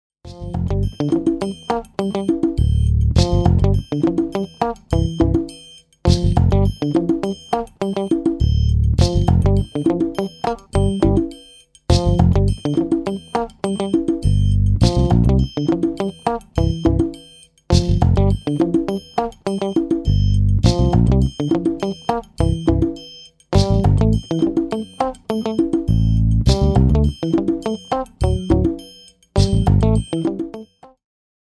A play-along track in the style of funk.